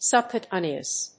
音節sùb・cutáneous 発音記号・読み方
/sʌbkjuːˈteɪnɪəs(米国英語), sʌˌbkju:ˈteɪni:ʌs(英国英語)/